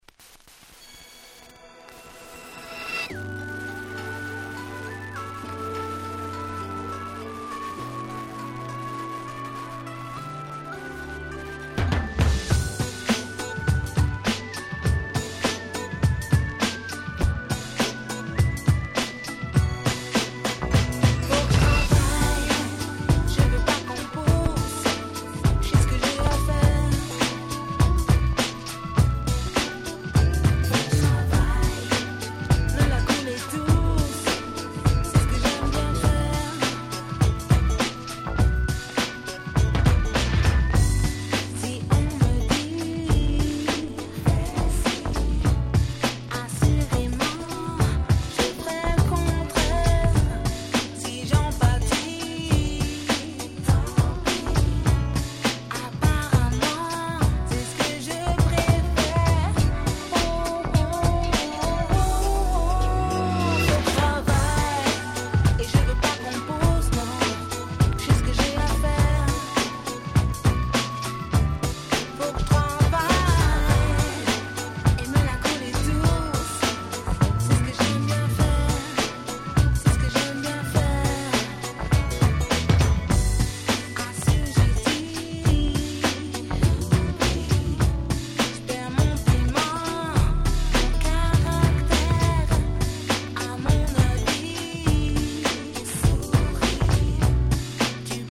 【Media】Vinyl 12'' Single
95' Nice French R&B !!
詳細は不明ですが、他のリリース作品を見る限りReggaeをバックボーンに持つ女性R&Bシンガー。
Groovyで切ないMelodyが堪らないめちゃくちゃ良い曲です！